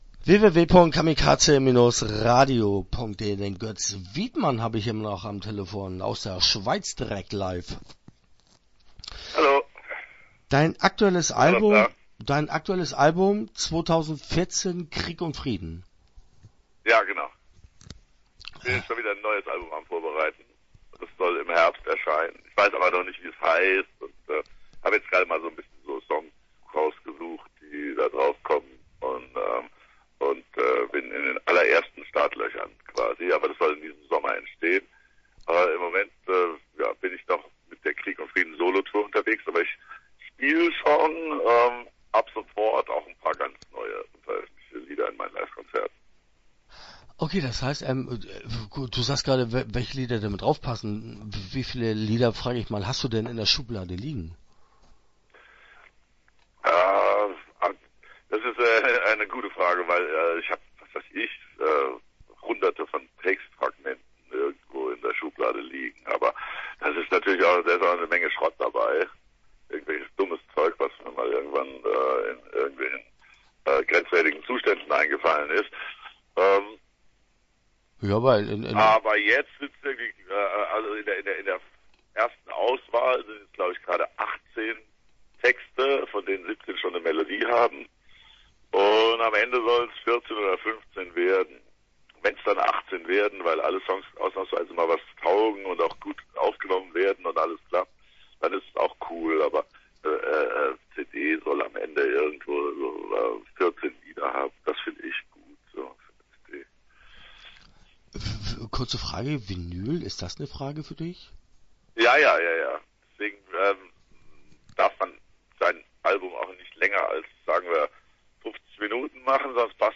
Interview Teil 1 (11:04)